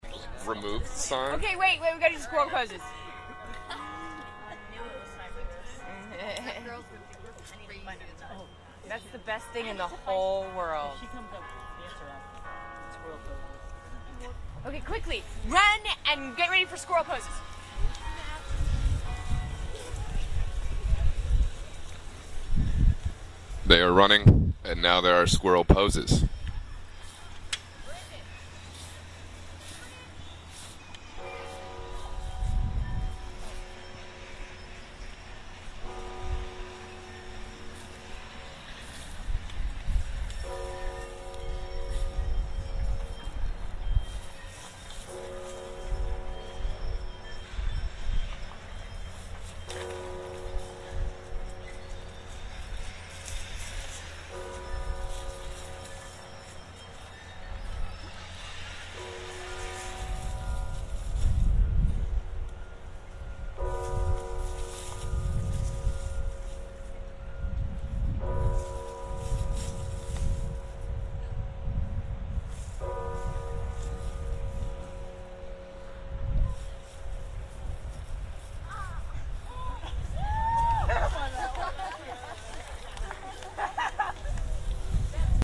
bells in background, construction machinery sounds, cheers, laughs and claps at end of poses